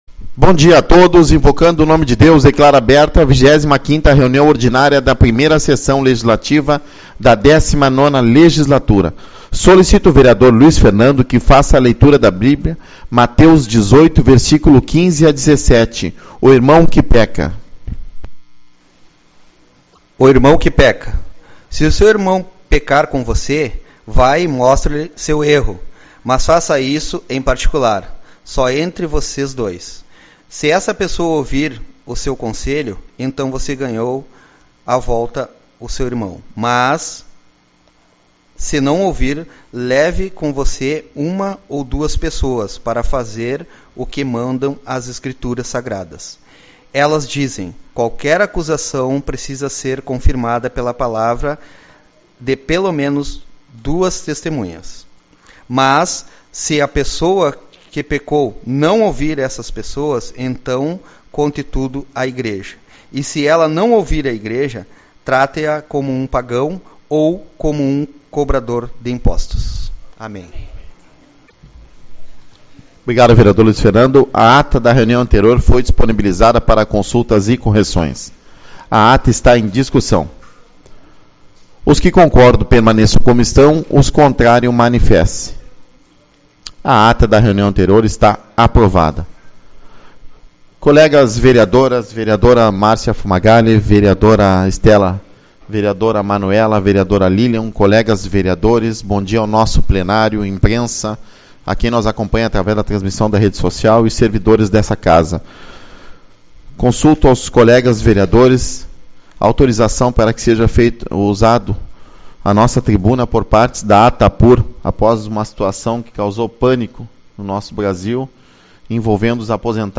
06/05 - Reunião Ordinária